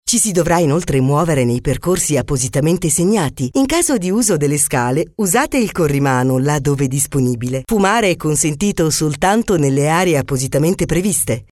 have been working at my professional home studio for 13 years, I record everything and have a flexible voice.
Sprechprobe: Industrie (Muttersprache):